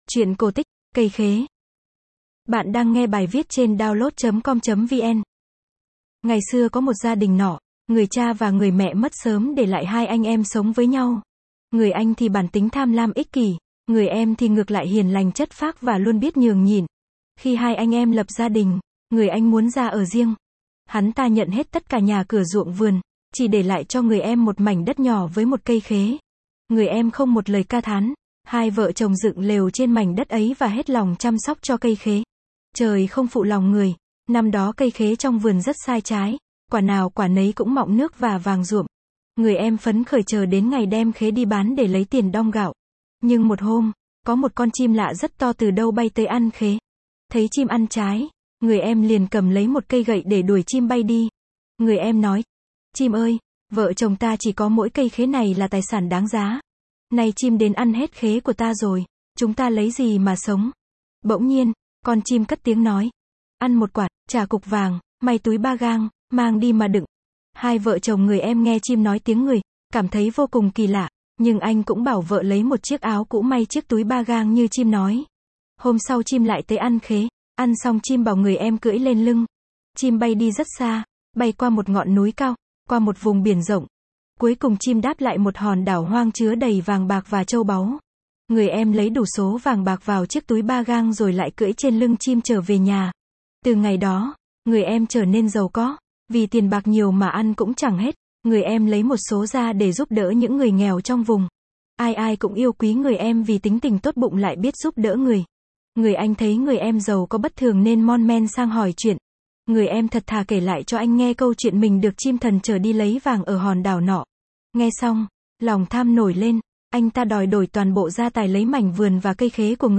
Sách nói | Truyện cây khế